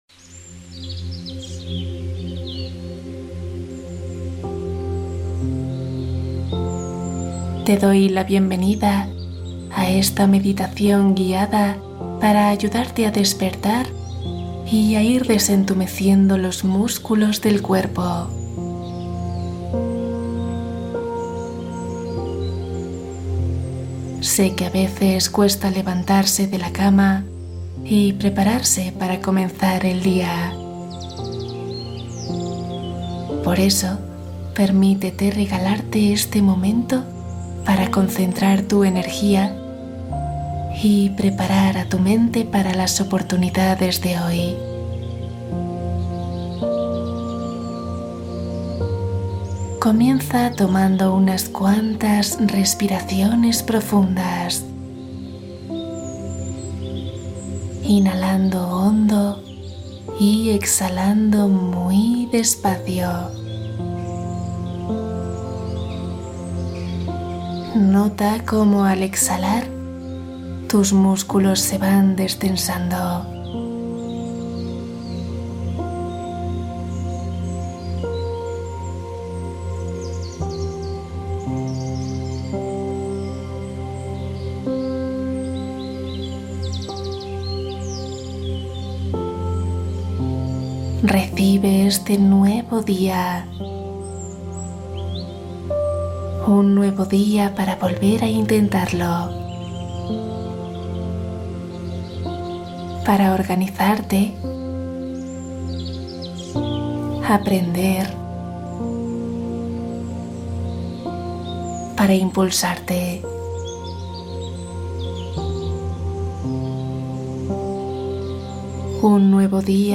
Alivia insomnio Meditación guiada para relajarte y dormir profundo